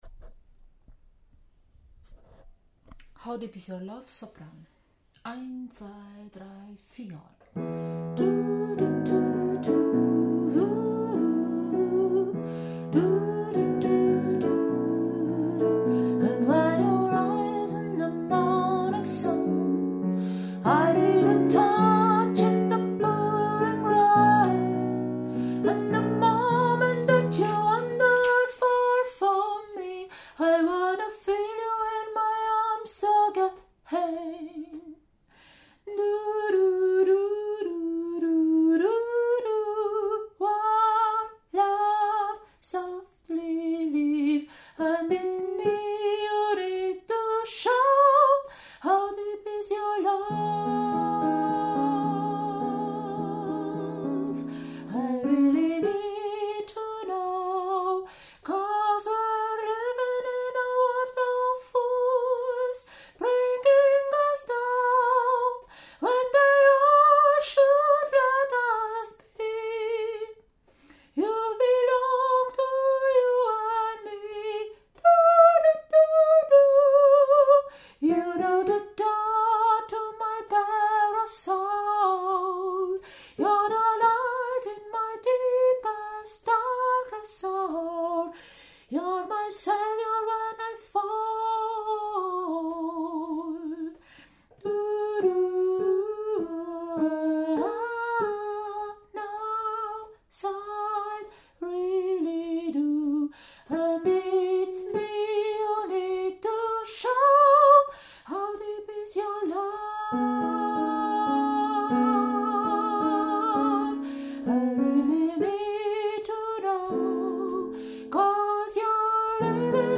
How deep is your love – Sopran